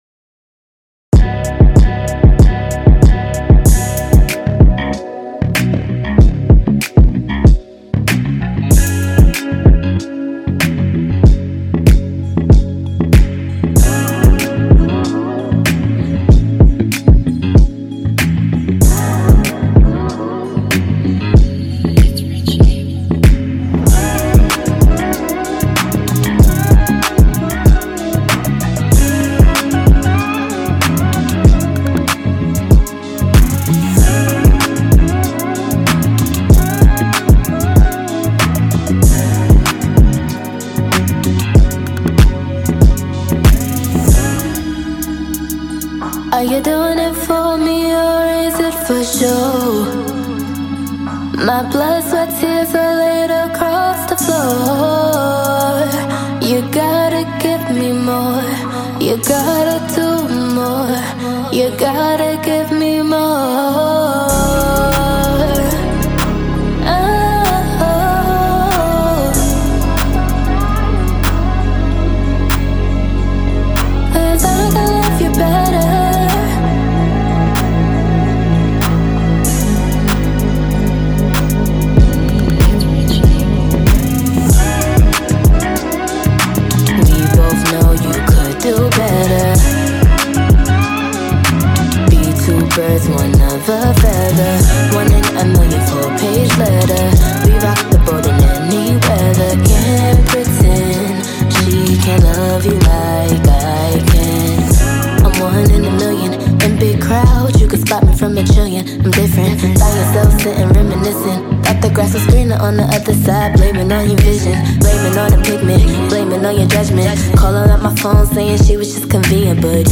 New remix